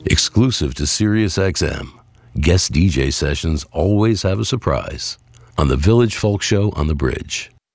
(audio capture from web stream)
13. promo (0:07)